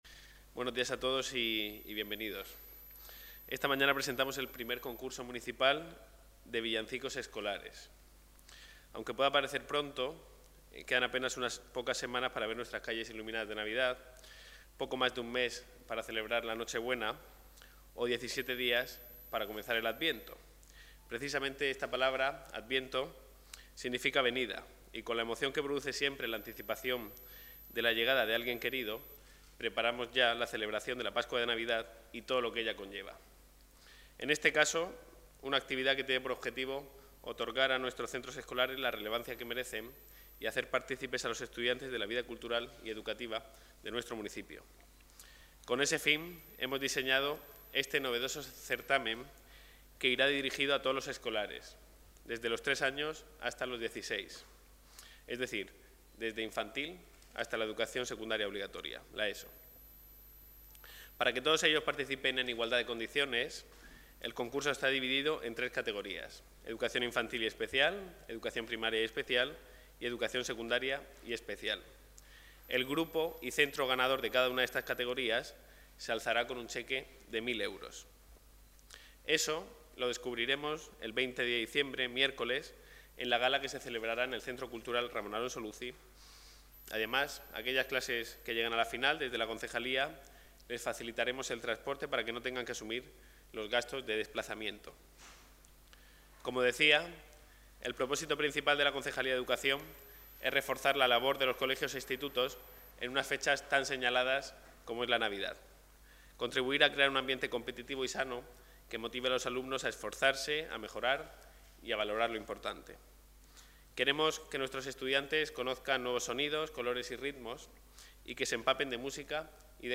Presentación I Concurso Escolar de Villancicos
Declaraciones